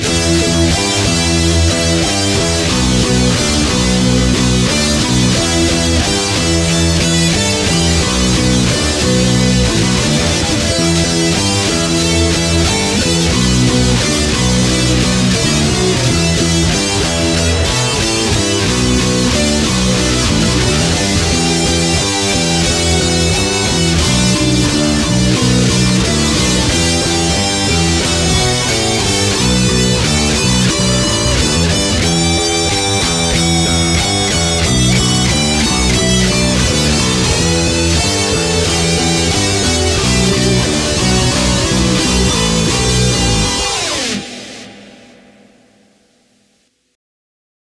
The bass line was written as I watched in disgust. I wanted a nice distortion and then layered two guitars over the top.
It's sloppy, but it's fun.